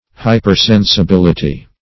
Hypersensibility \Hy`per*sen`si*bil"i*ty\
hypersensibility.mp3